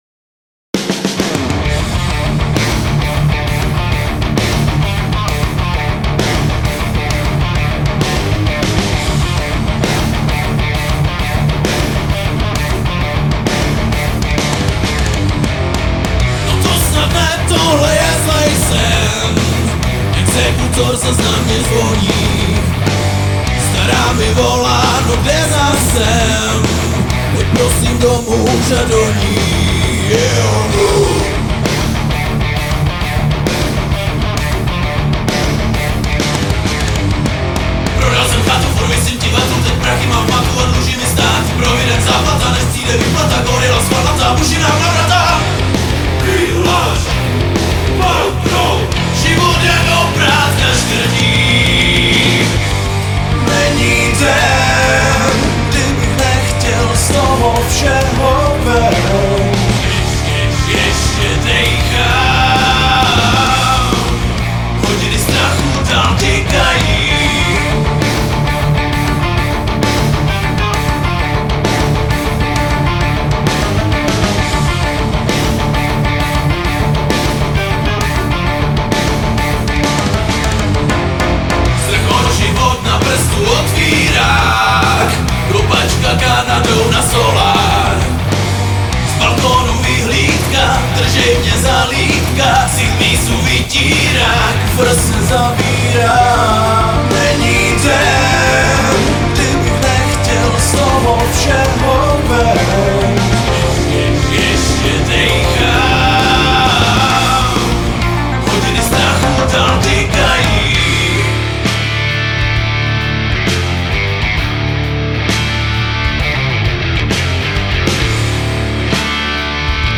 na stará kolena jsem si odběhl od jazzu zase k bigbítu